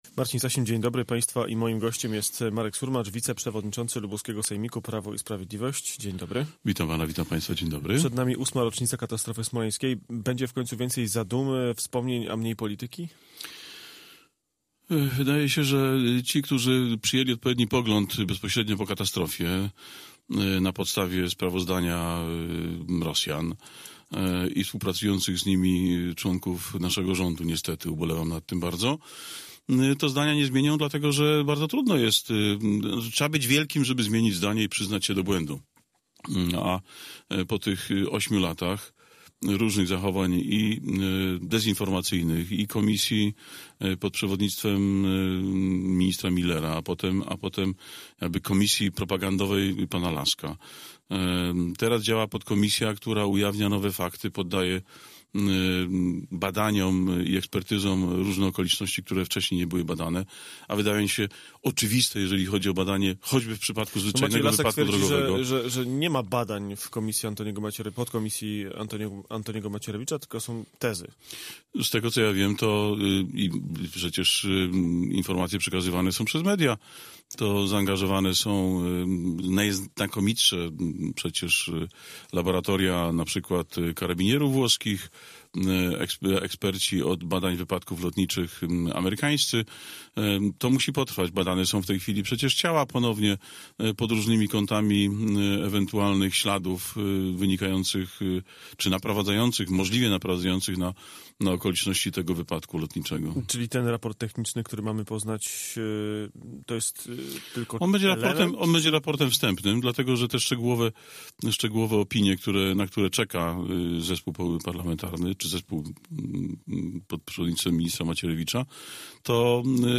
Z wiceprzewodniczącym sejmiku lubuskiego (PiS) rozmawia